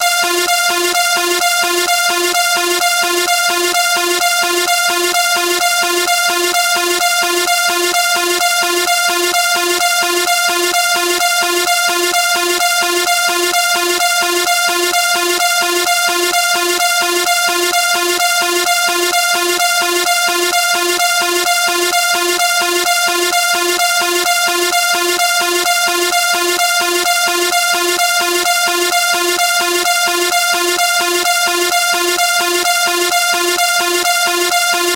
シンセサイザーで作られた警報音です。